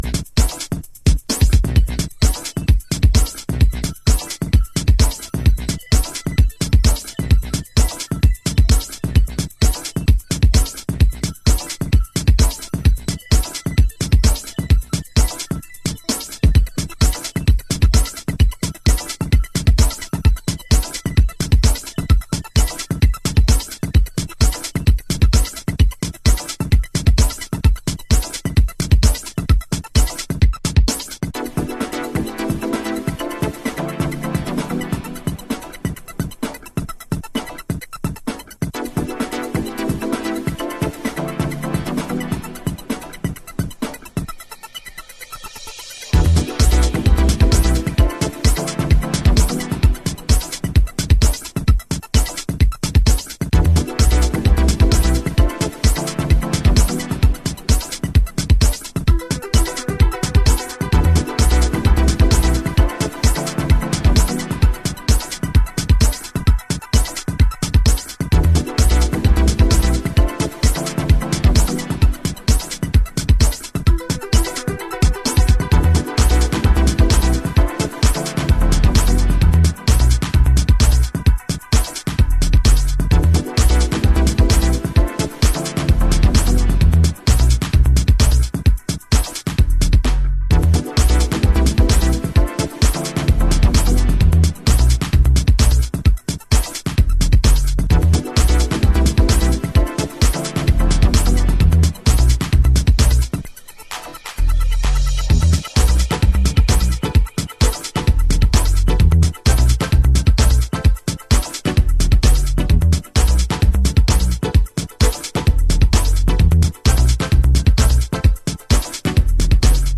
House / Techno
このエレクトロとテクノを折衷したグルーヴはハマります。